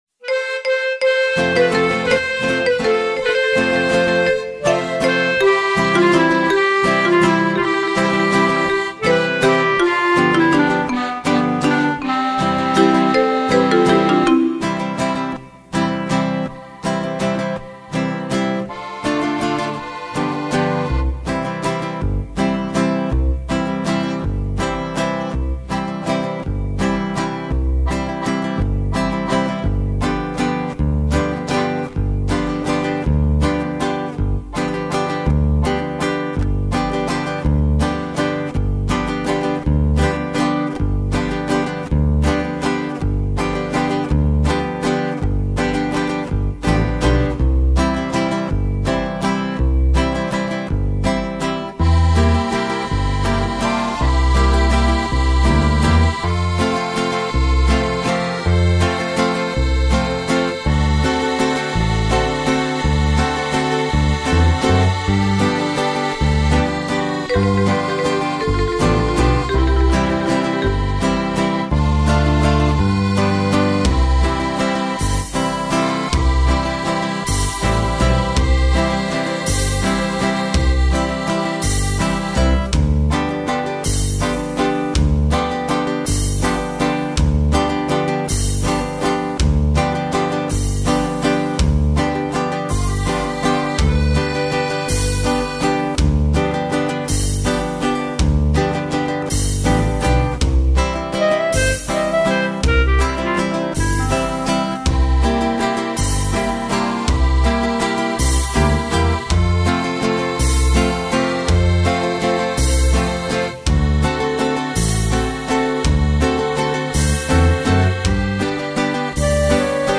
El acompañamiento: